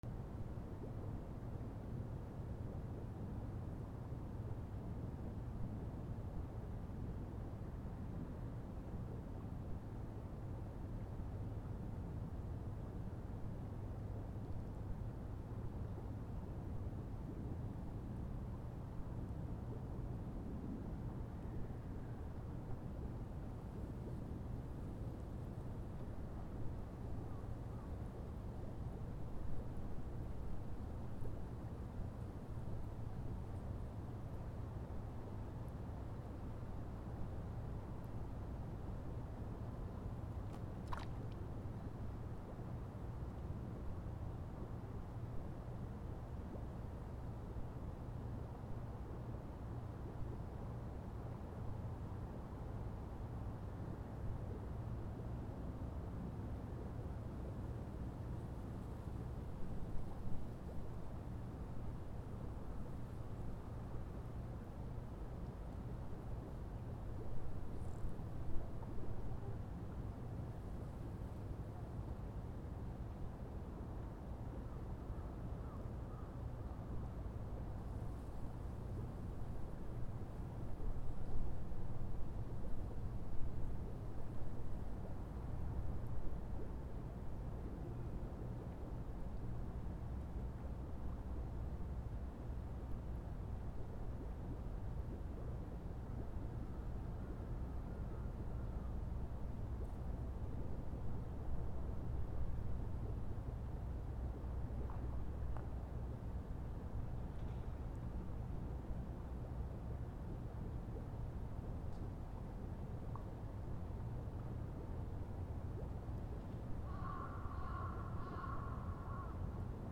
/ B｜環境音(自然) / B-45 ｜自然その他
池 バックグラウンド
静かめ 時折魚の水音